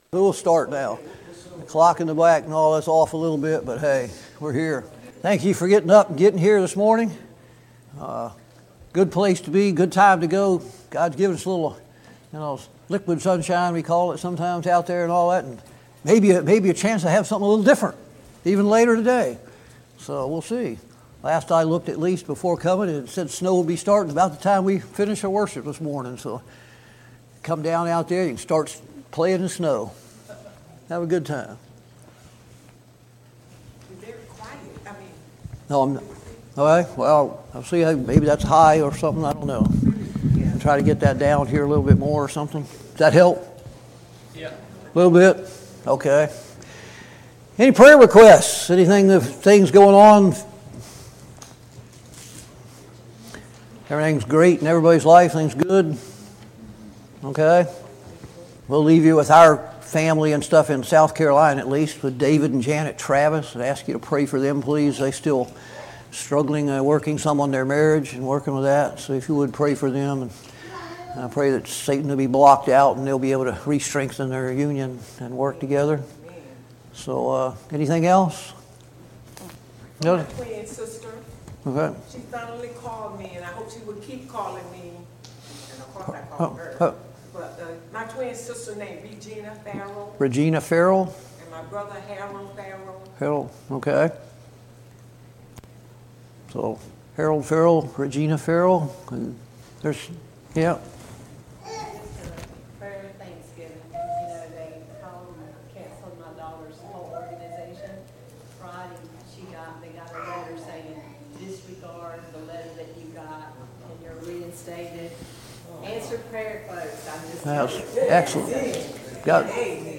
Study of Jeremiah Service Type: Sunday Morning Bible Class « Study of Paul’s Minor Epistles